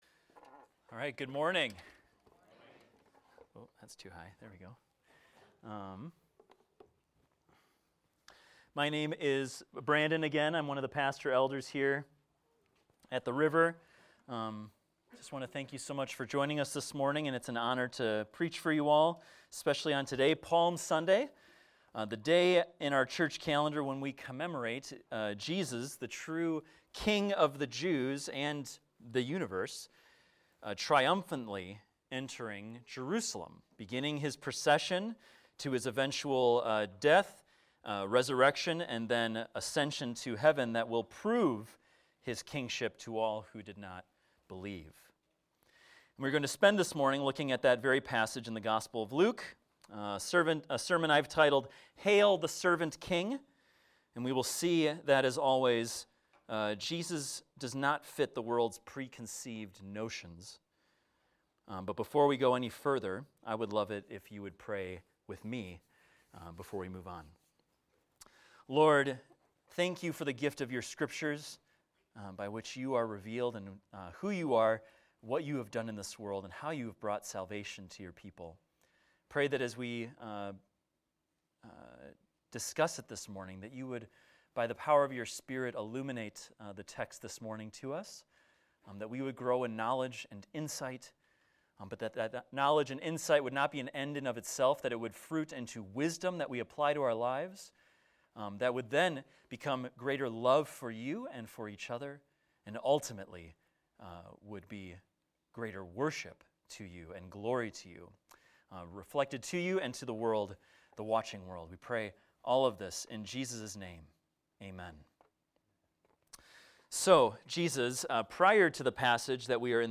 This is a sermon about the Triumphal Entry of Jesus titled "Hail the Servant King!"